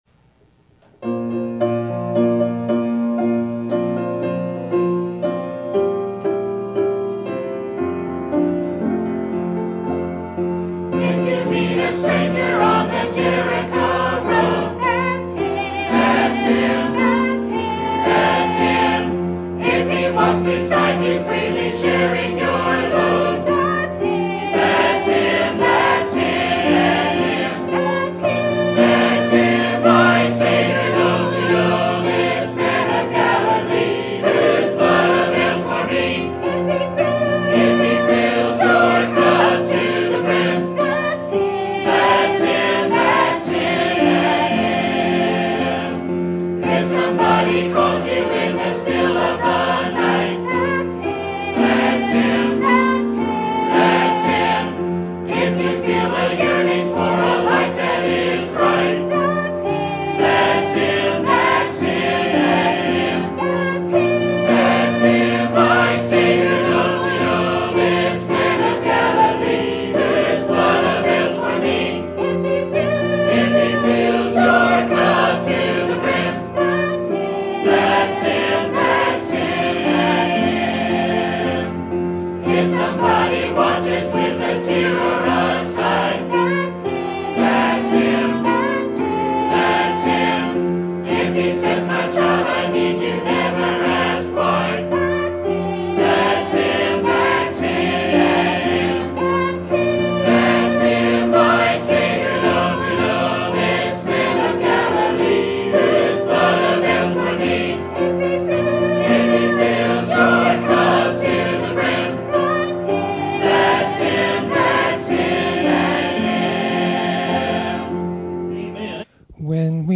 Sermon Date: Sunday, July 1, 2018 - 6:00pm Sermon Title: How to Pray for Missionaries and Those in the Ministry?